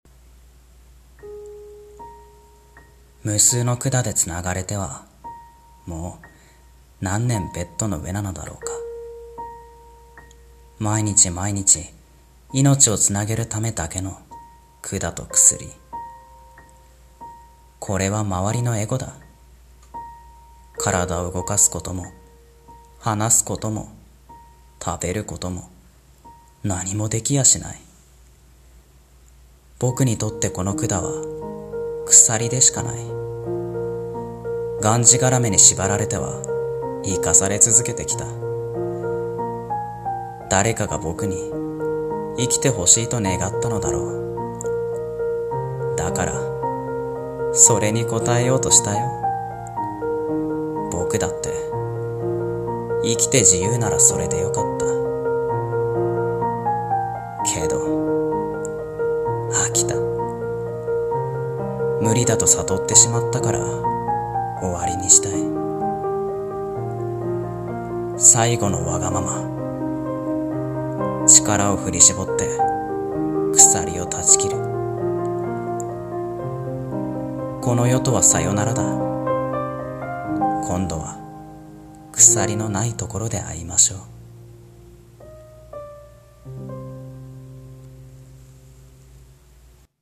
【声劇】命を縛る鎖